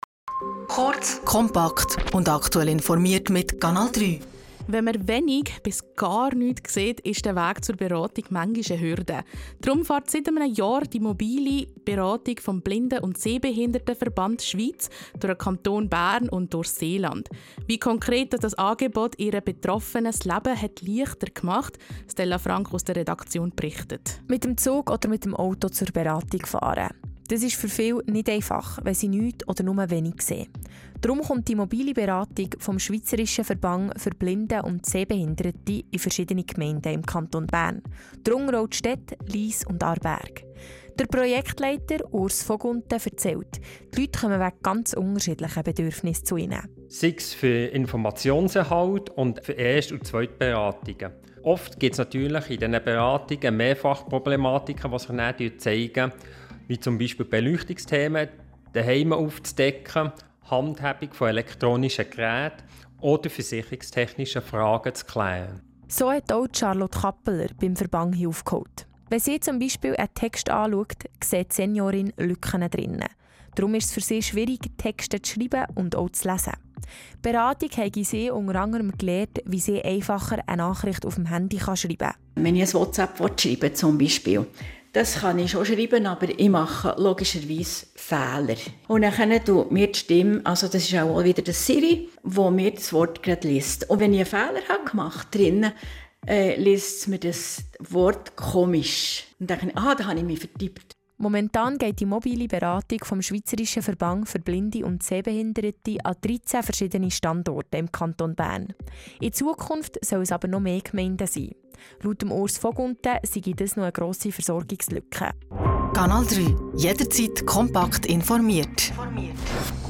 Radiobeitrag über die Mobile Beratung vom 14.8.25 auf Canal+